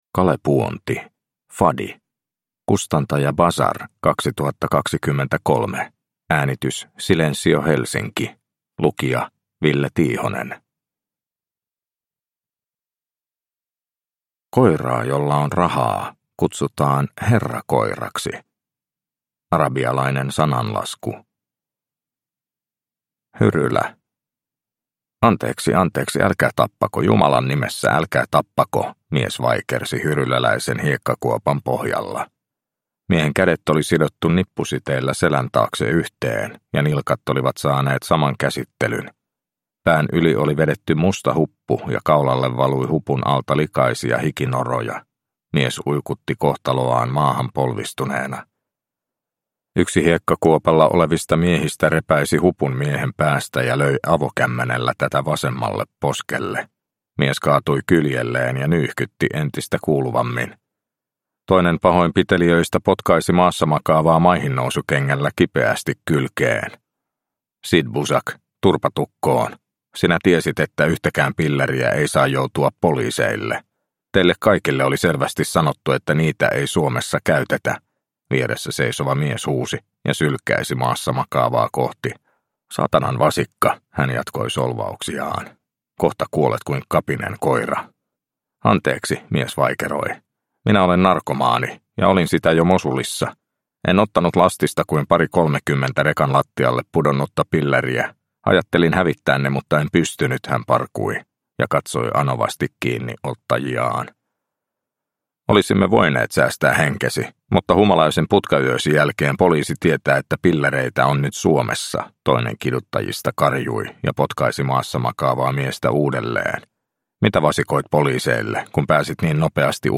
Fadi – Ljudbok – Laddas ner